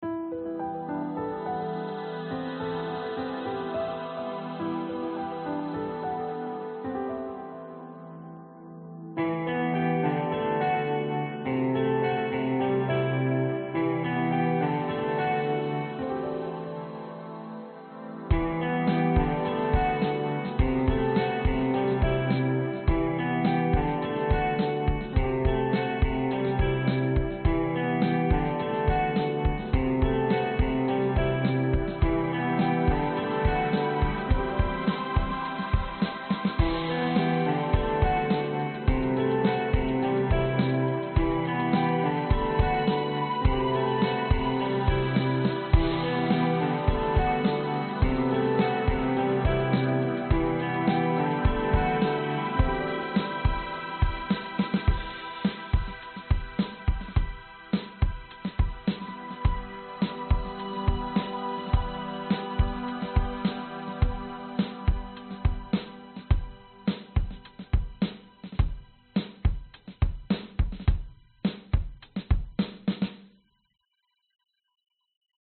描述：Em的调子。
茎是我能做的最干的，但有一些内在的混响。
Tag: 合成器 吉他 钢琴 循环播放